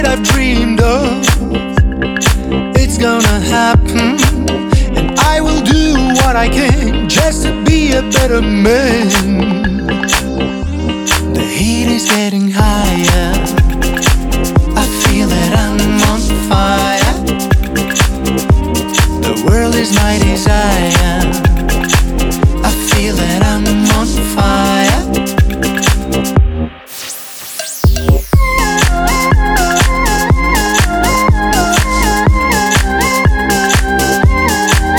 Indie Rock Alternative
Жанр: Рок / Альтернатива